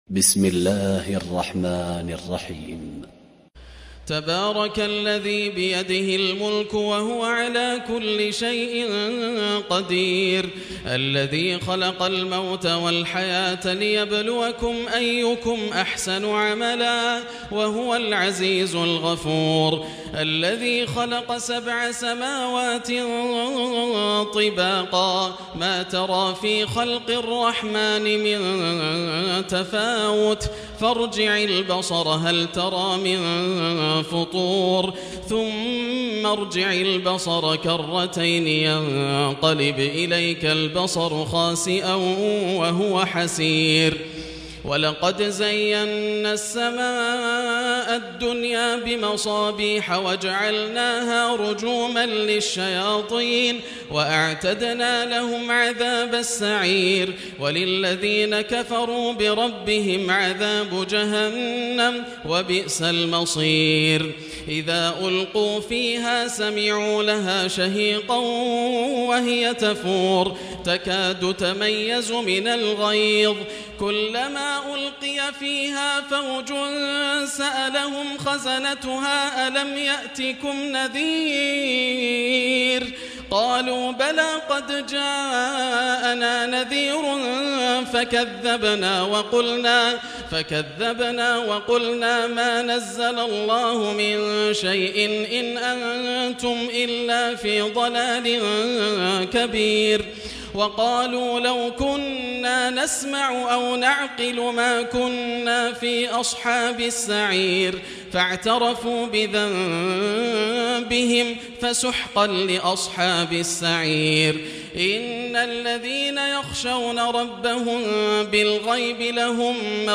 تراويح ليلة 28 رمضان 1440هـ من سورة الملك الى نوح Taraweeh 28 st night Ramadan 1440H from Surah Al-Mulk to Nooh > تراويح الحرم المكي عام 1440 🕋 > التراويح - تلاوات الحرمين